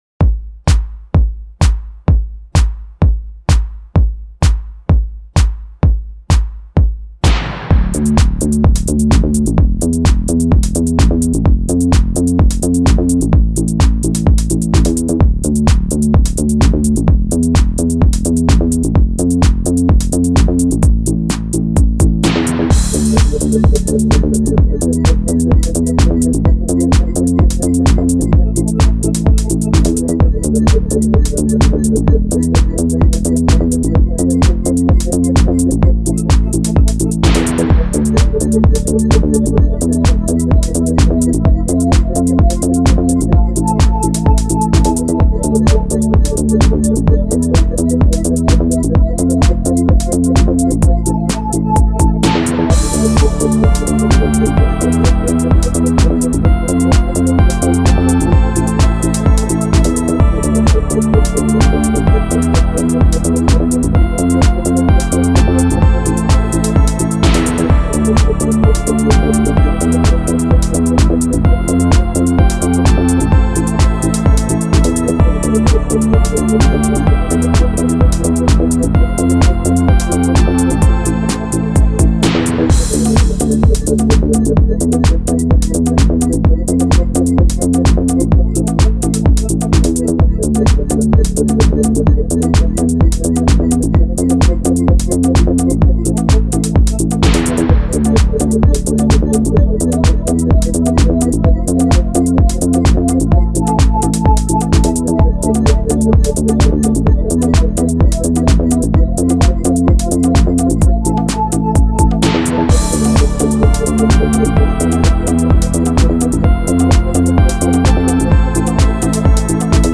finest electronic music.